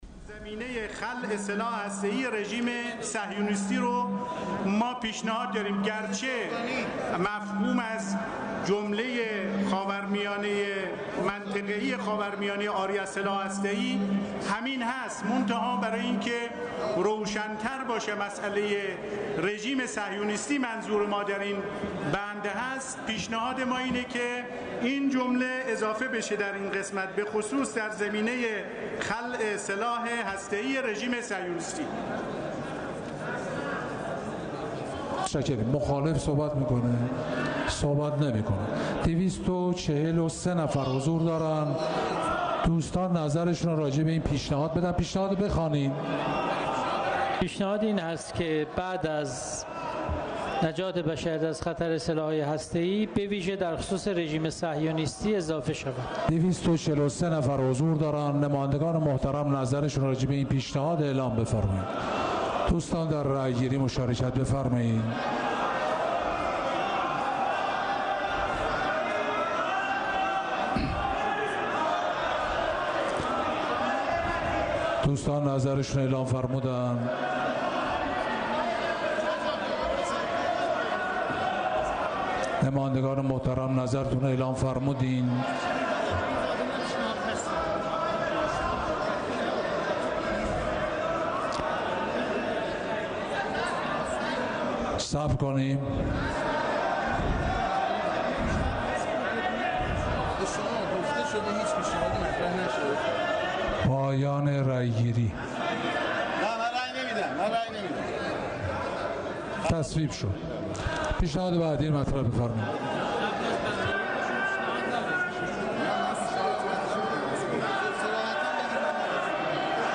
جزئیات طرح جنجالی اجرای برجام را در جلسه علنی امروز مجلس شورای اسلامی به ریاست علی لاریجانی می‌شنوید.